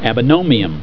Pronunciation
(am be NOE nee um)